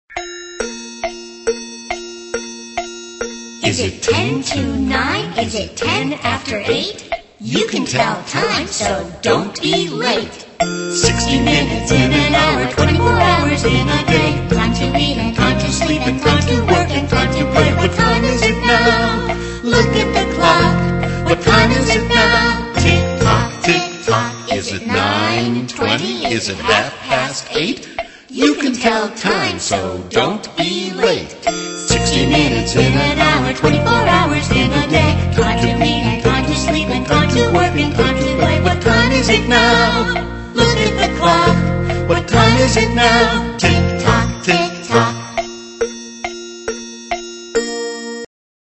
在线英语听力室英语儿歌274首 第98期:Is it ten to nine的听力文件下载,收录了274首发音地道纯正，音乐节奏活泼动人的英文儿歌，从小培养对英语的爱好，为以后萌娃学习更多的英语知识，打下坚实的基础。